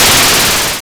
Explosion2.wav